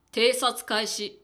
ボイス 00:00 / 00:00 ダウンロード 中性_「わぁあお！」